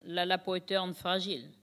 Sallertaine
Catégorie Locution